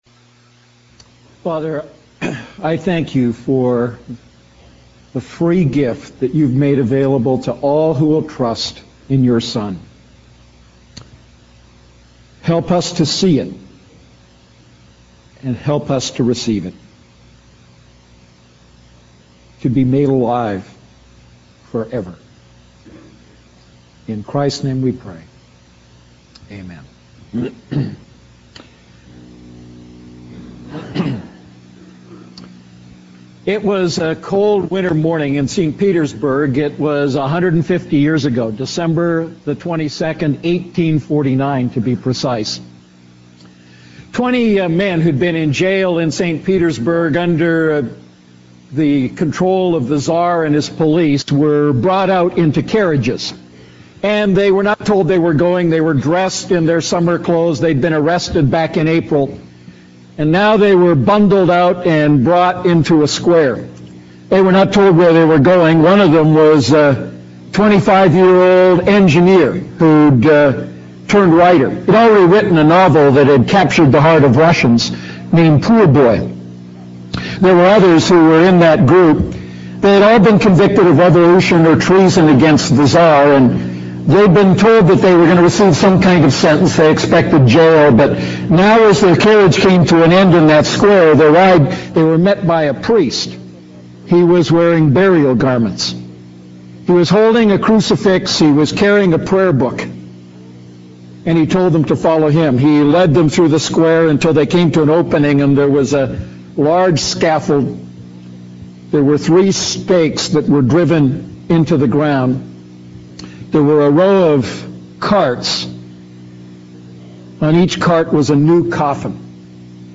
A message from the series "Ephesians Series."